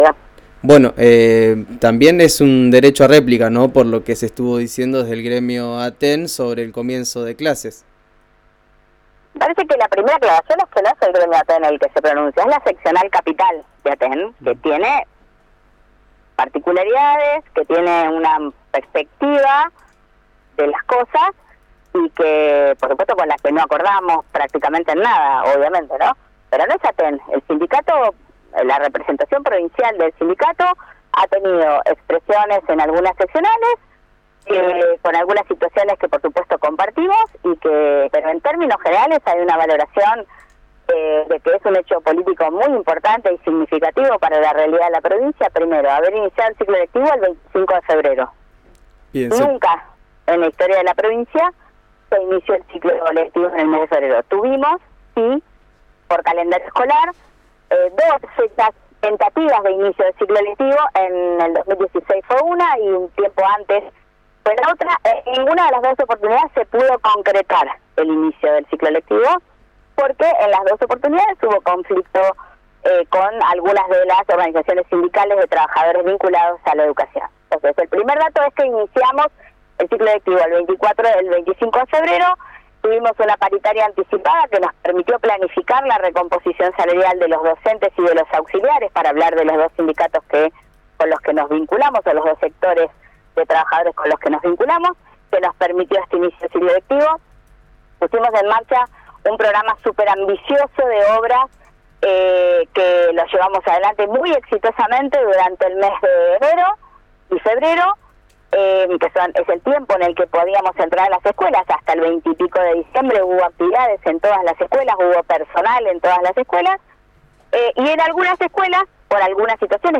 Escuchá a  Soledad Martínez, en RADIO RÍO NEGRO:
La funcionaria dijo que fueron 32 escuelas las que no pudieron comenzar este martes en toda la provincia, de las 780 escuelas que hay en total.